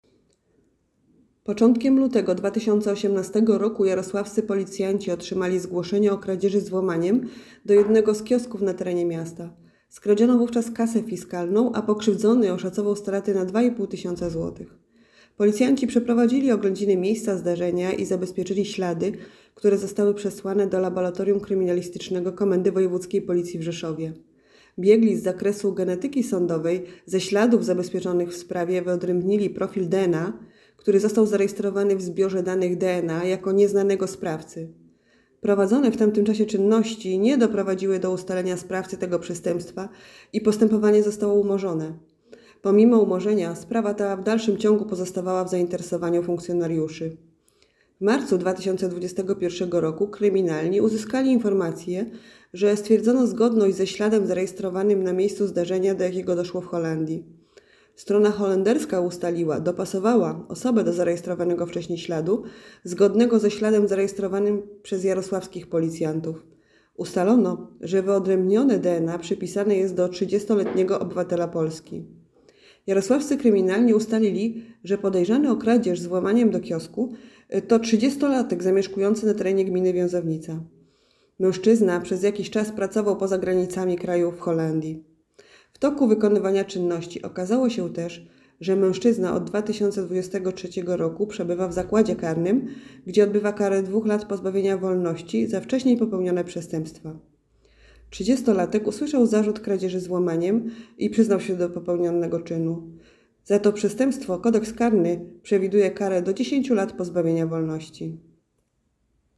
Opis nagrania: Nagranie głosowe tekstu dot. rozwiązania przez kryminalnych sprawy kradzieży z włamaniem sprzed 7 lat.